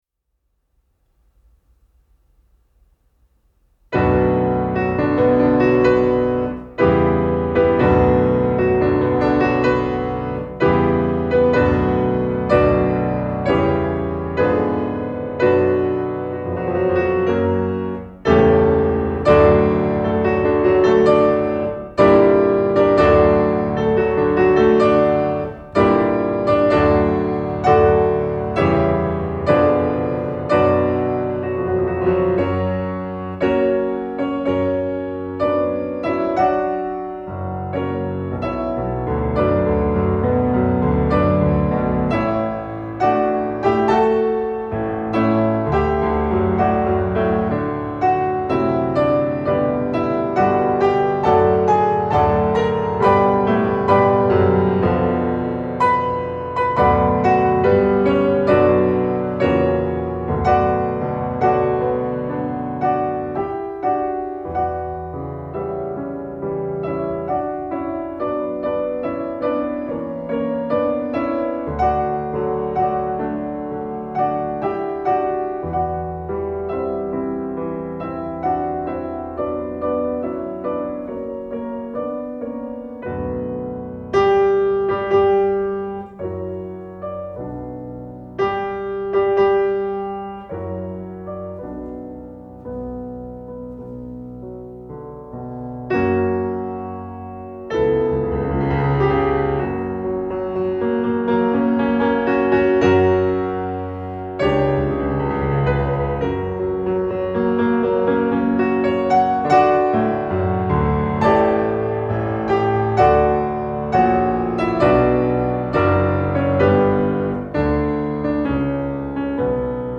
bring warmth and conviction to each piece
pianist